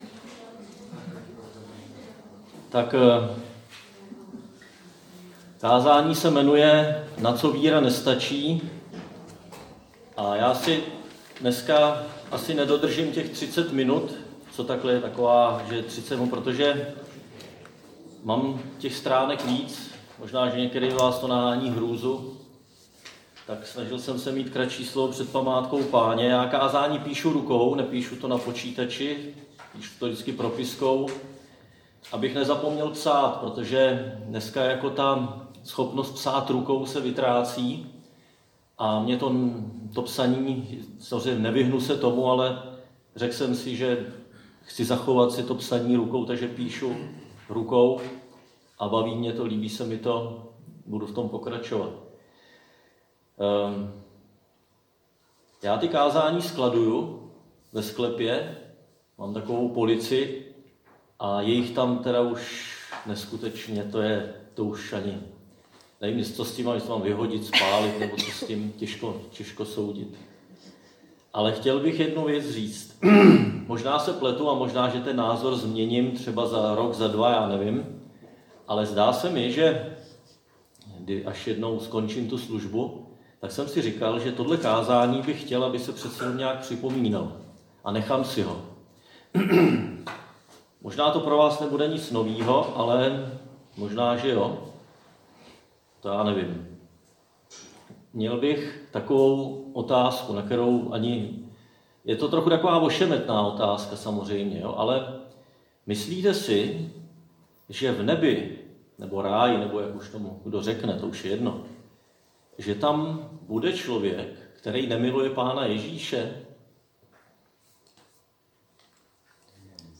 Křesťanské společenství Jičín - Kázání 5.1.2020